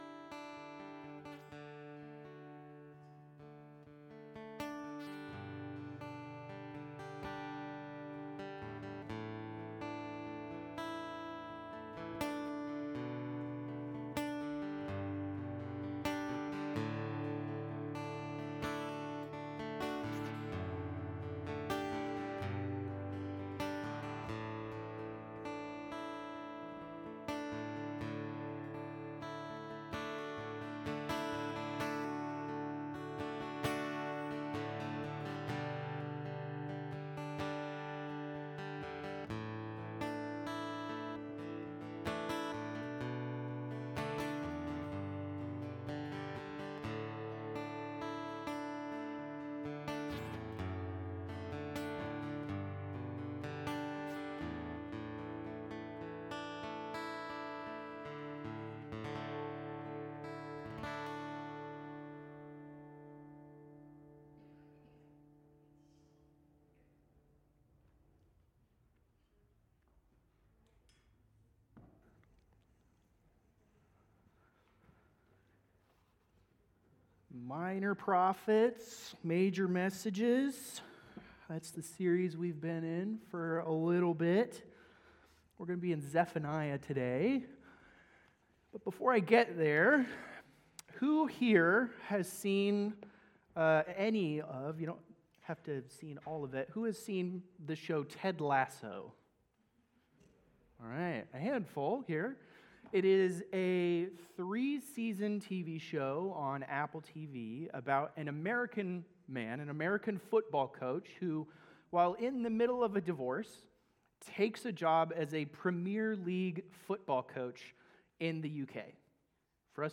Sermons by First Free Methodist Spokane